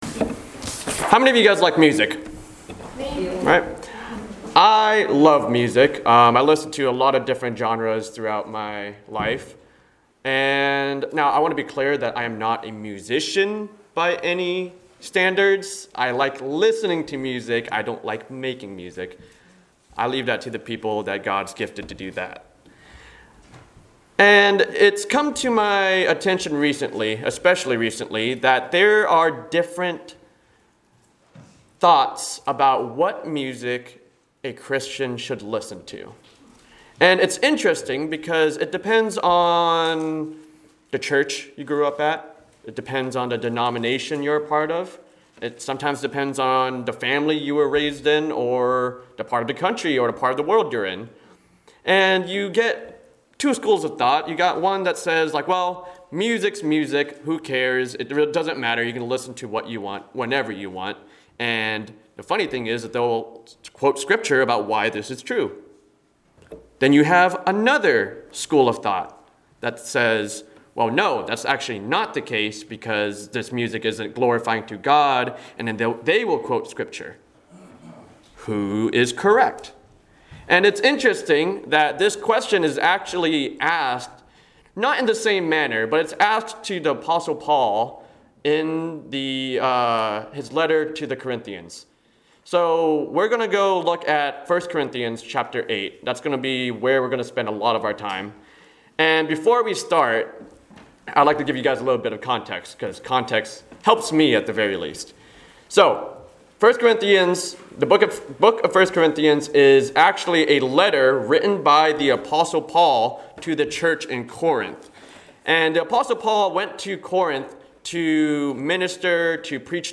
Service Type: Sunday Morning Worship Topics: Refrain lest we cause our brother to stumble